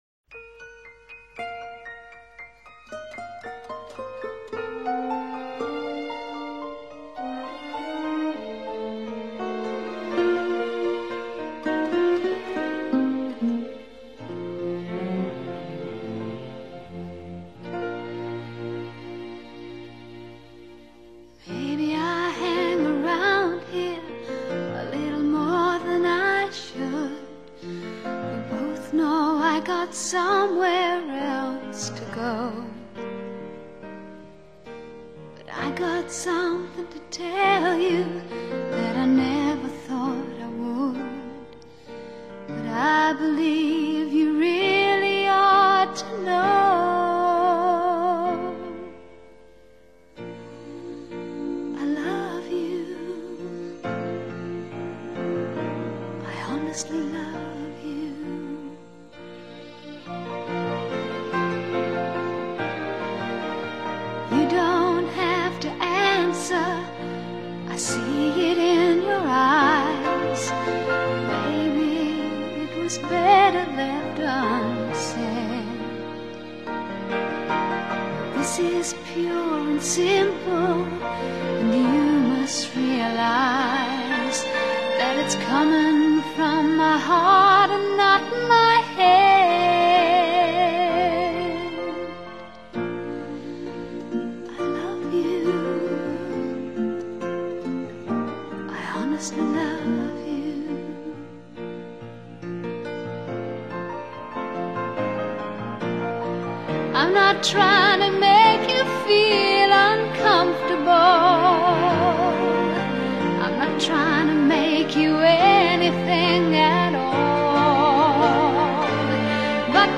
Pop, Country Pop